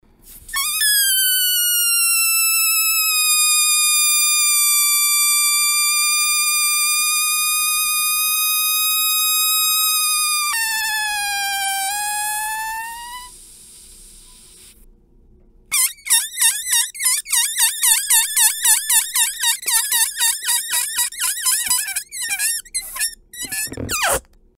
Спускание воздуха из воздушного шарика